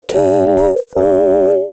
Kategori Mobiltelefon